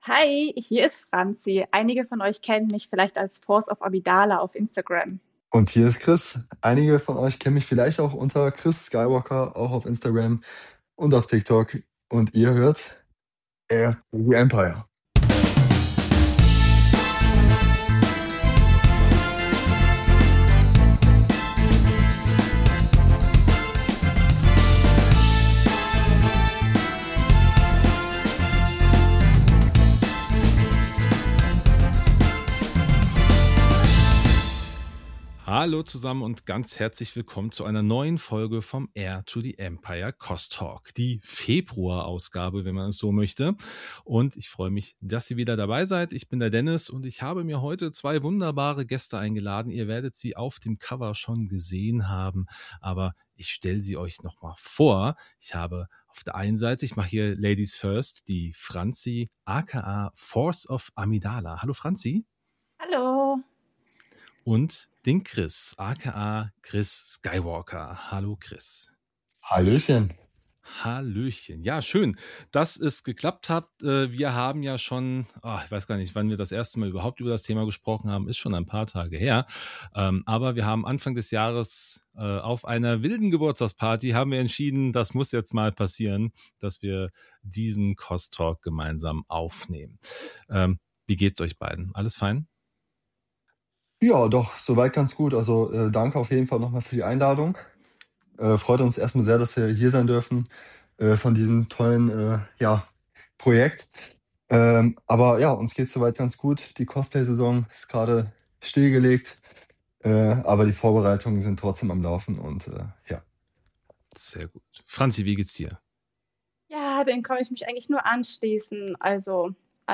Cos-Talk – das Cosplay-Interview-Format von Heir to the Empire nimmt euch mit in die faszinierende Welt der Star Wars-Cosplayer.